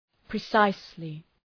Προφορά
{prı’saıslı}